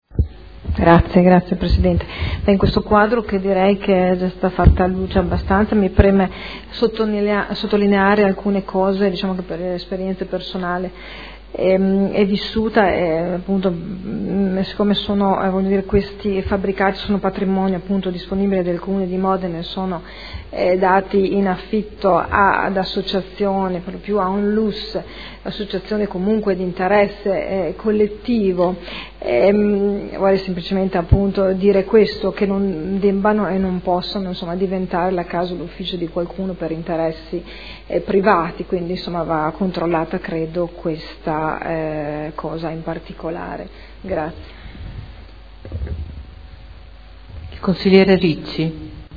Sandra Poppi — Sito Audio Consiglio Comunale